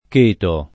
chetare v.; cheto [ k % to ] — es. con acc. scr.: Chétati se non vuoi che ti sien messe Le grappe alle mascelle [ k % tati S e nnon v U0 i ke tti S iem m %SS e le g r # ppe alle mašš $ lle ] (D’Annunzio)